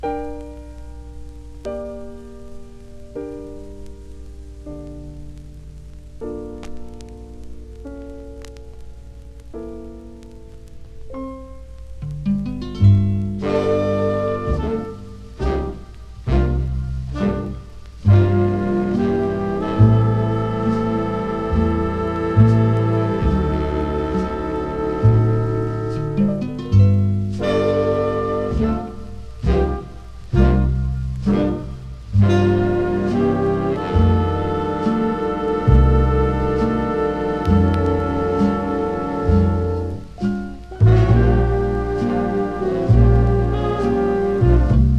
楽しく、ダイナミック、そして美しさもある1957年ニューヨーク録音。
Jazz, Swing, Big Band　France　12inchレコード　33rpm　Mono